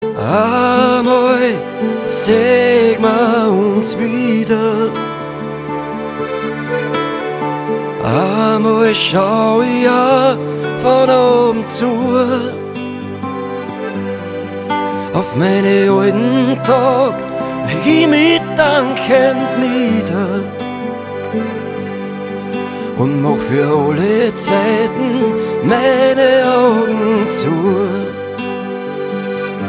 Gattung: Blasorchester (mit Gesang)
Besetzung: Blasorchester
Tonart: F-moll